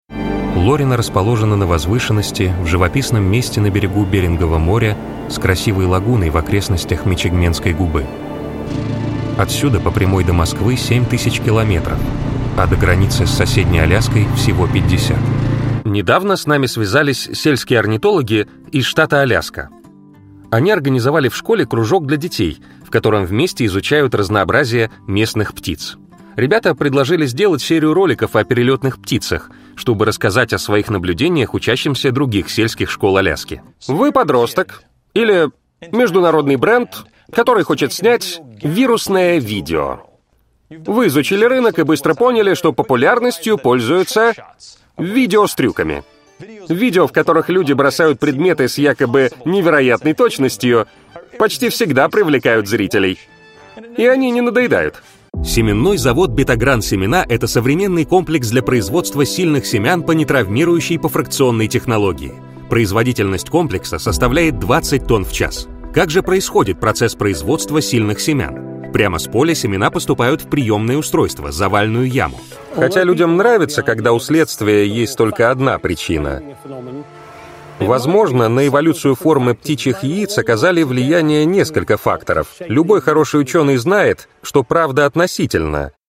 Диктор и актёр озвучивания.
Тракт: Микрофоны: Neumann TLM-103 Обработка: Long VoiceMaster Звуковая карта: SSL 2+ Акустическая кабина